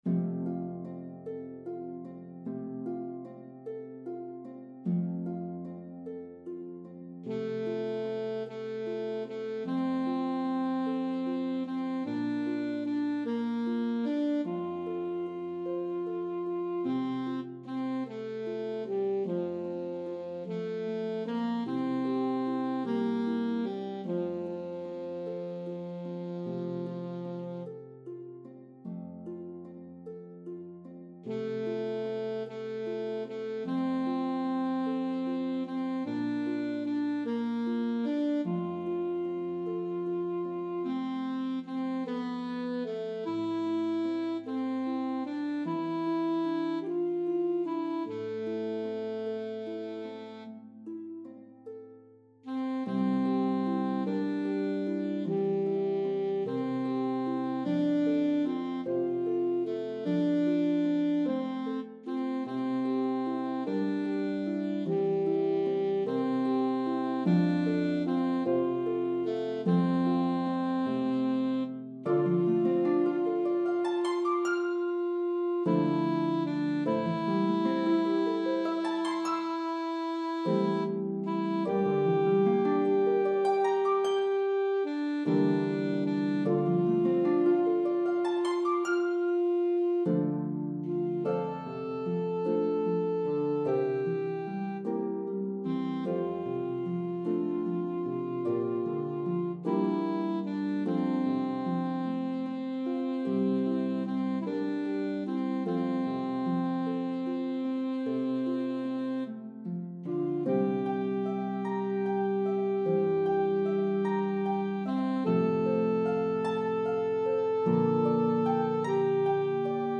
The melody of the two verses varies in rhythm & pitches.